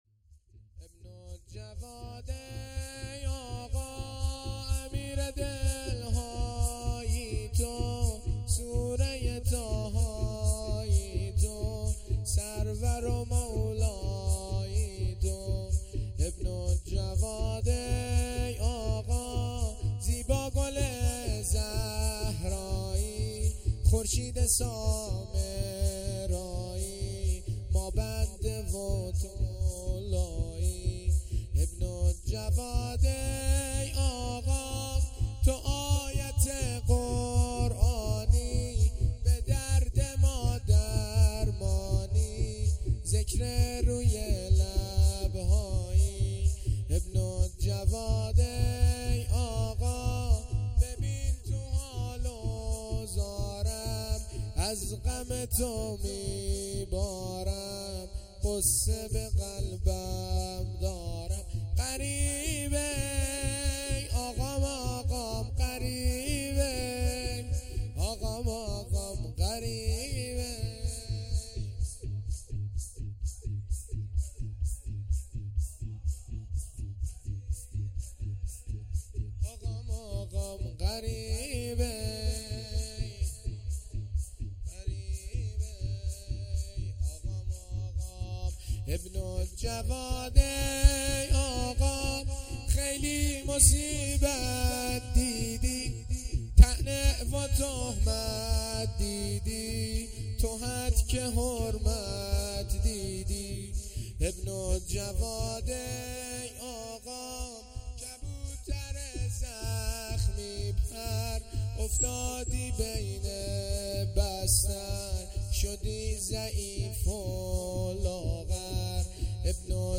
شب شهادت امام هادی(ع)